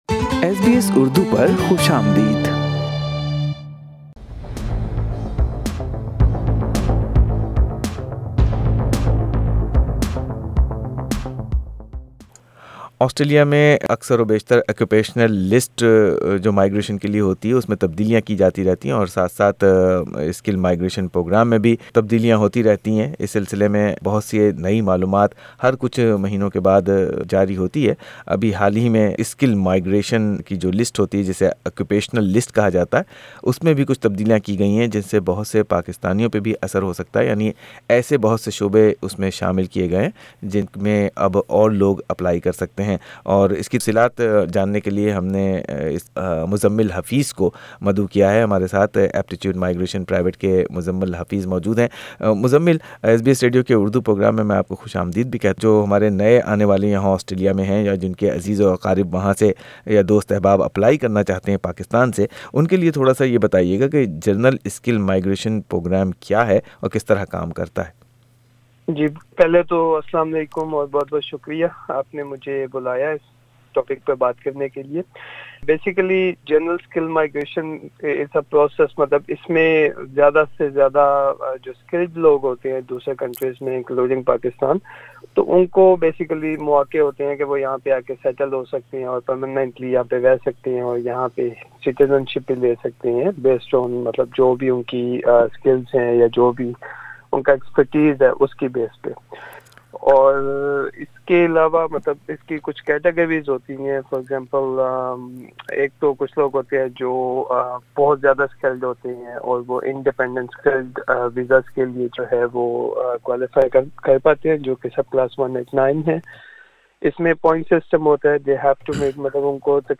SBS Urdu talks to a migration agent to find out what are the new changes in the general skilled migration list. 36 occupations have been added to the medium and long-term list which can lead to permanent residency.